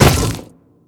biter-roar-behemoth-5.ogg